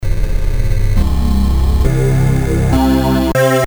Following a full 44 kHz 24 bits STEREO recording from original machines, this will be updated when new sounds (for suggestions drop me a edit LINE).
edit ST-01 SAMPLES FROM ROLAND D50 A classic digital synthesizer from 1987 based on LA synthesis, also well-known to retrogamers for the Roland MT32 module.